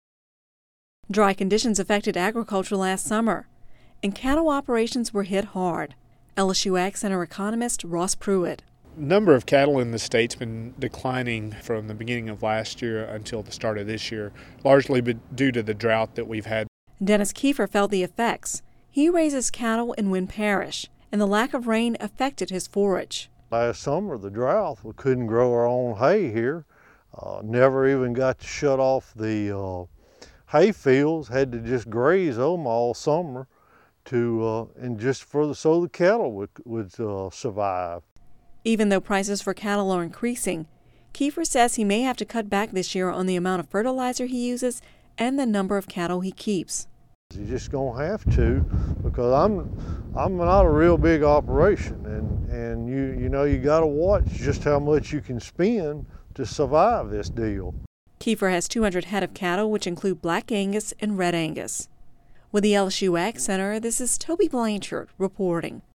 (Radio News 02/28/11) Dry conditions affected Louisiana agriculture last summer, and cattle operations in the state were hit hard.